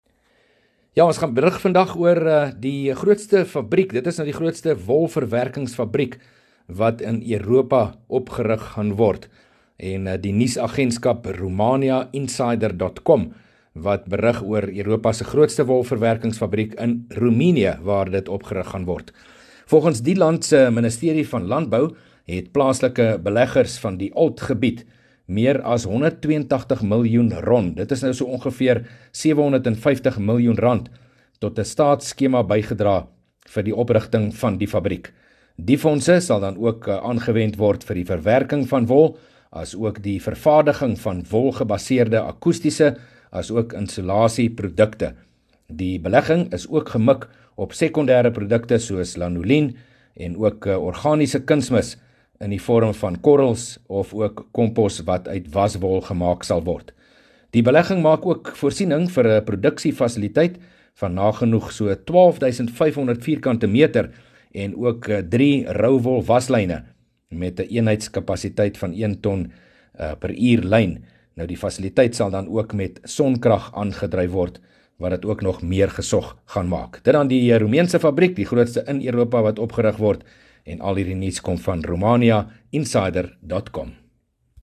berig oor ‘n wolverwerkingsfabriek, die grootste van sy soort in Europa